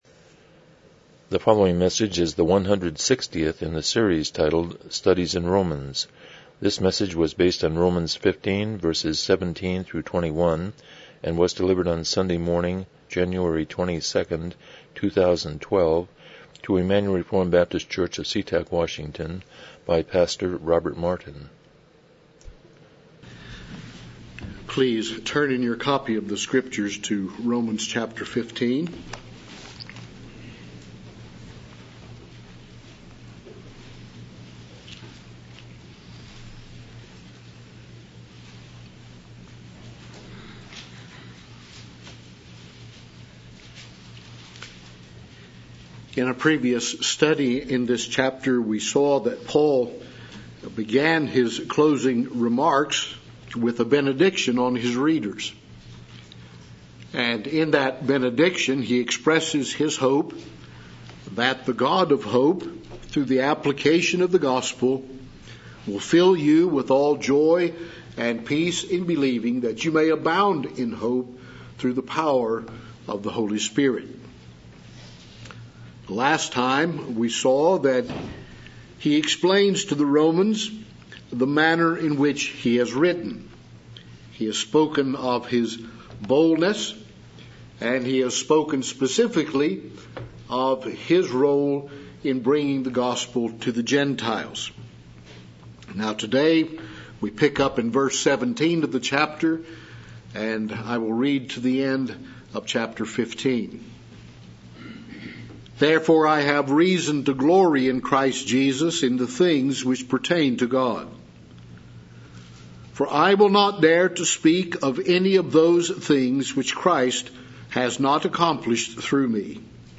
Romans 15:17-21 Service Type: Morning Worship « 138 Chapter 28